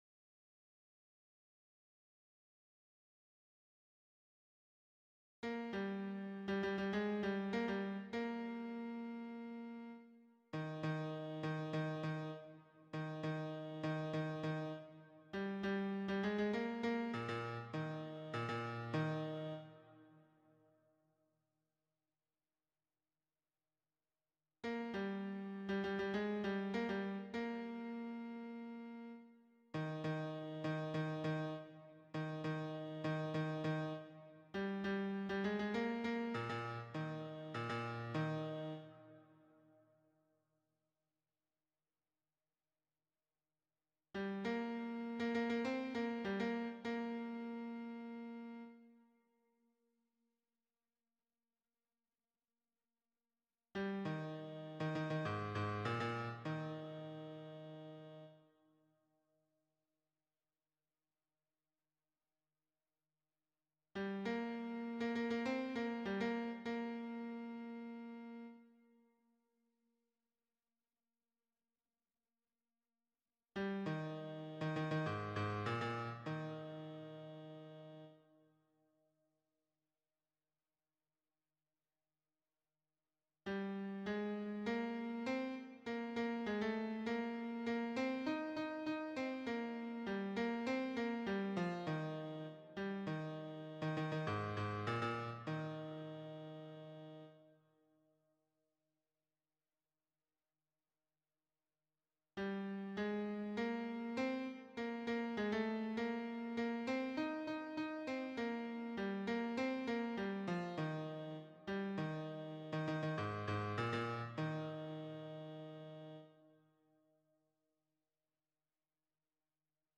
Répétition SATB4 par voix
Basse